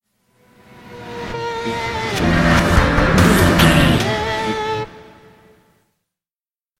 Thriller
Aeolian/Minor
synthesiser
drum machine
electric guitar